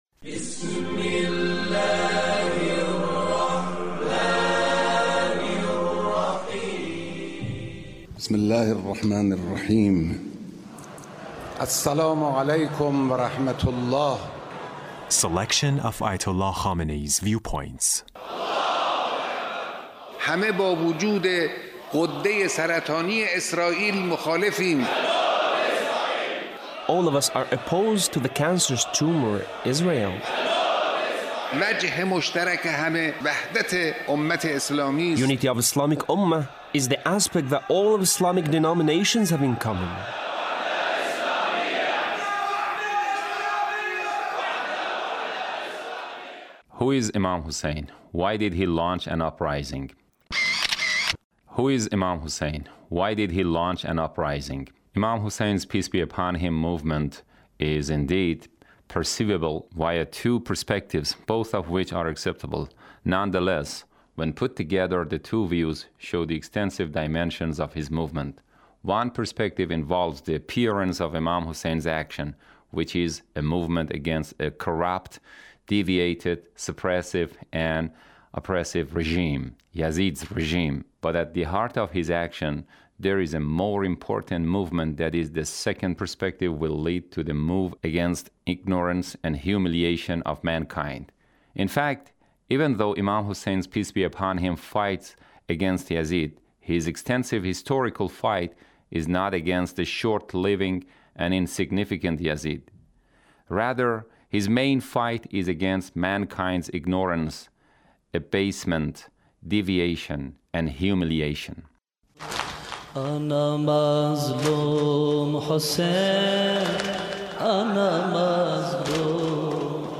Leader's Speech (1766)